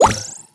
bo_mine_spawn_01.wav